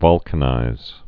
(vŏlkə-nīz)